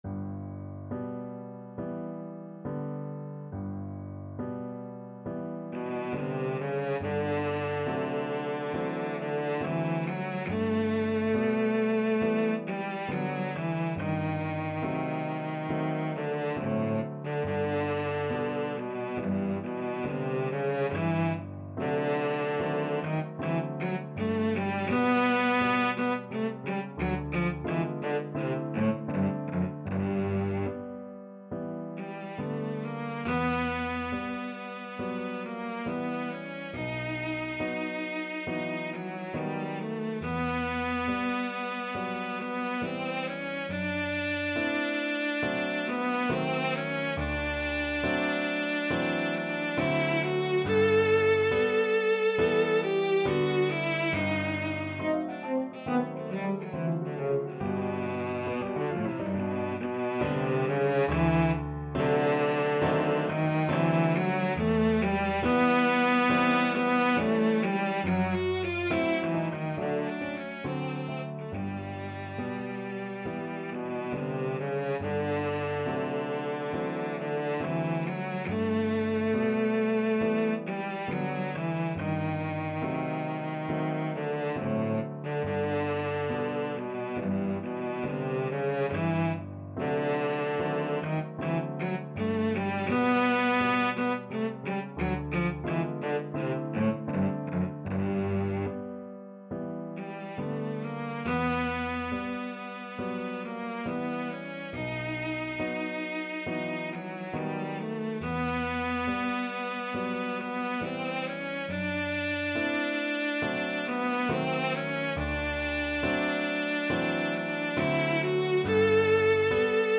Cello
G major (Sounding Pitch) (View more G major Music for Cello )
Andante non troppo con grazia =69
4/4 (View more 4/4 Music)
Classical (View more Classical Cello Music)